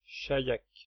Chaillac (French pronunciation: [ʃajak]
Fr-Paris--Chaillac.ogg.mp3